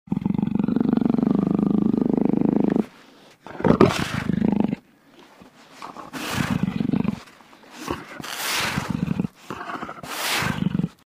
Звуки ягуара
Тихое рычание